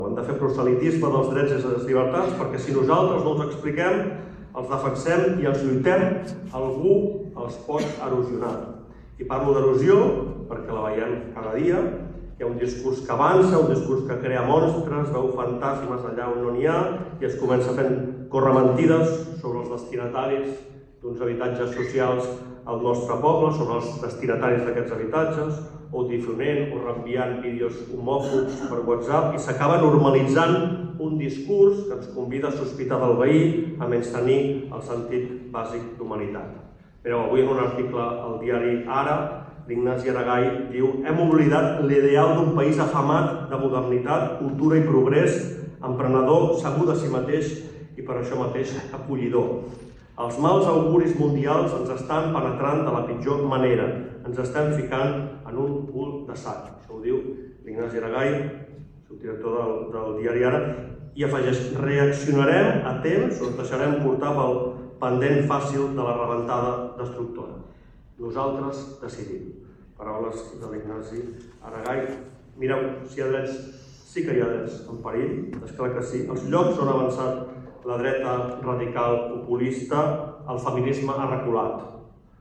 La Sala Albéniz ha acollit aquest dissabte 7 de març a les dotze del migdia l’acte institucional del 8M a Tiana, que enguany ha posat el focus en la pressió estètica i el cos.
“Veig pocs homes”, ha lamentat l’alcalde de Tiana, Isaac Salvatierra, en el seu discurs, afegint que també li hauria agradat veure més joves: